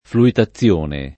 [ fluita ZZL1 ne ]